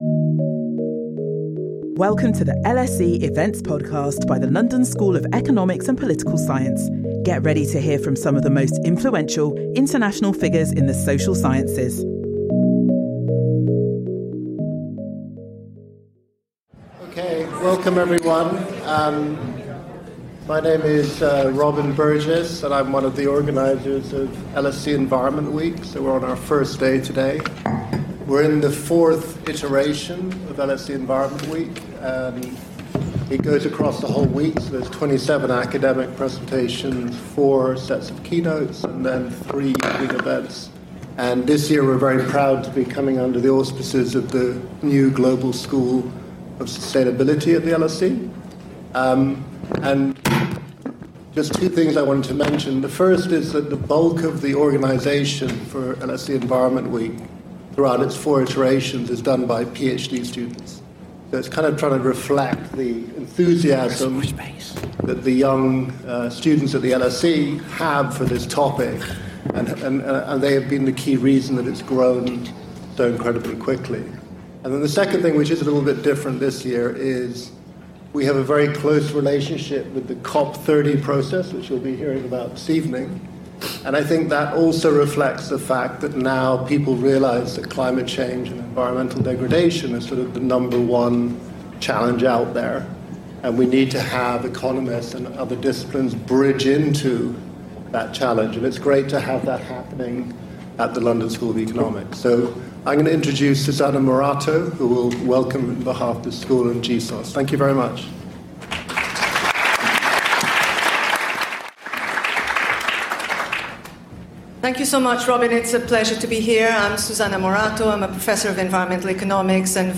As countries prepare to submit enhanced nationally determined contributions (NDCs), our panel explores how climate action can drive innovation, job creation, and long-term resilience, particularly in emerging and developing economies.